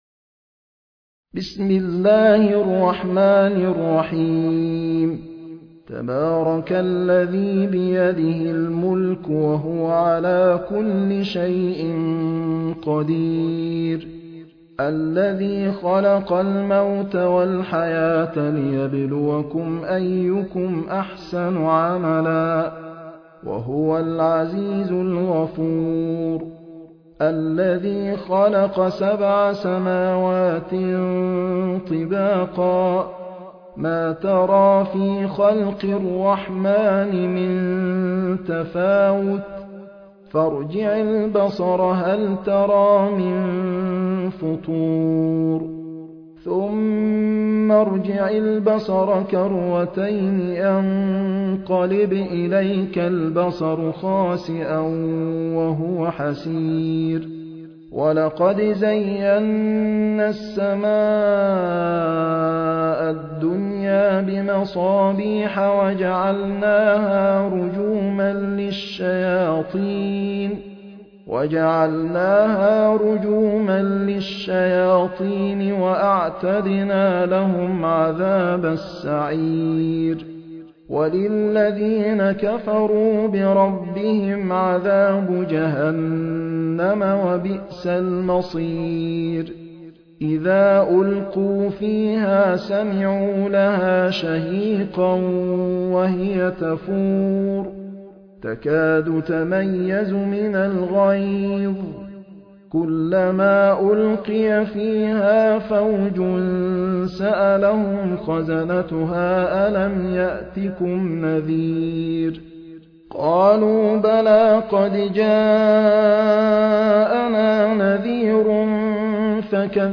High Quality Quranic recitations and Islamic Lectures from selected scholars
المصحف المرتل - حفص عن عاصم - Al-Mulk ( Dominion )